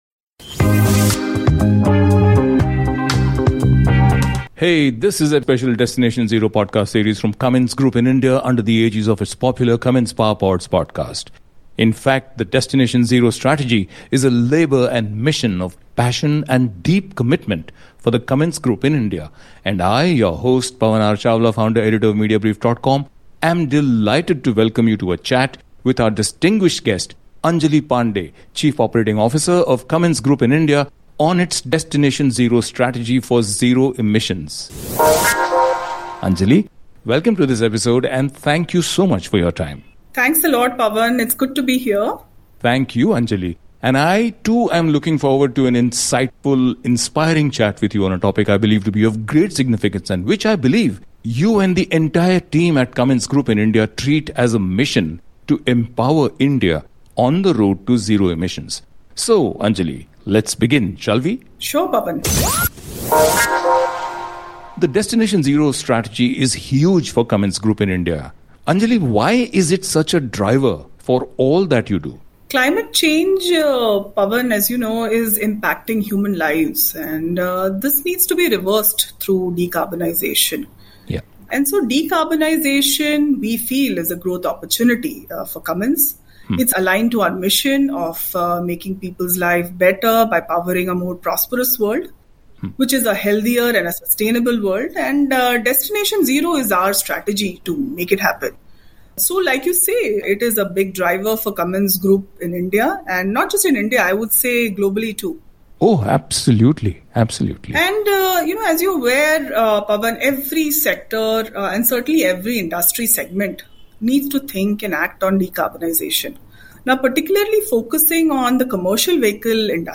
This, and more, in a deep-dive conversation with one of India’s most respected leaders in the space.